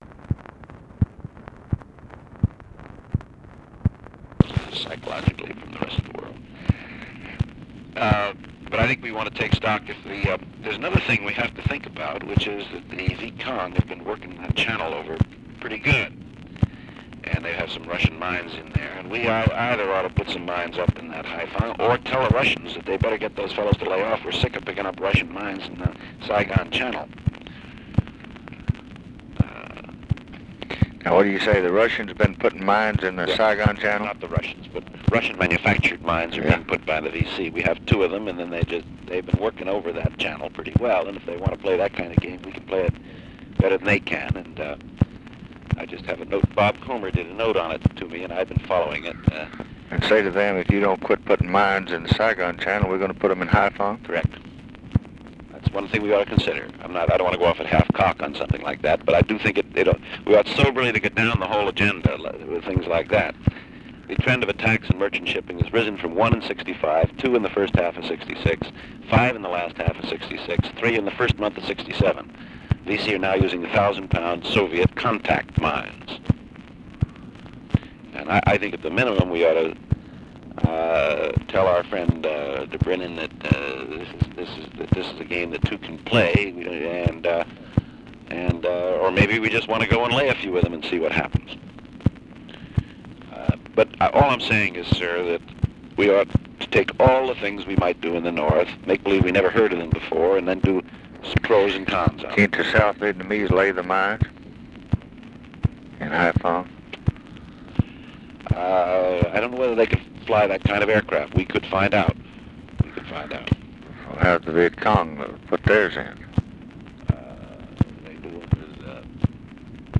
Conversation with WALT ROSTOW, February 15, 1967
Secret White House Tapes